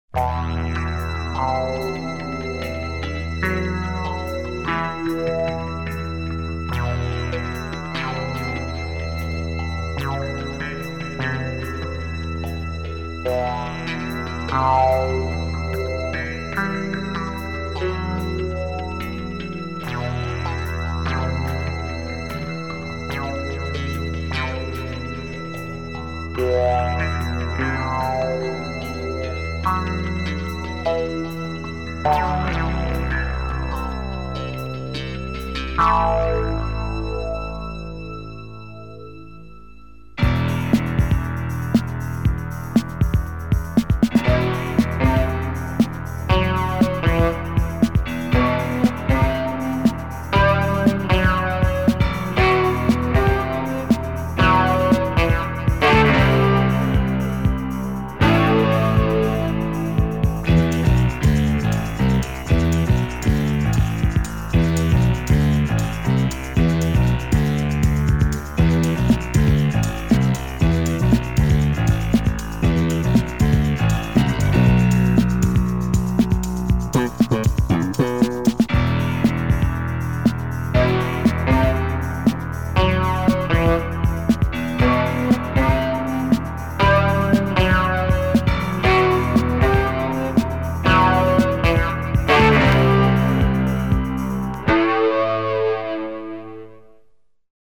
Killer funk tunes with breaks on this record !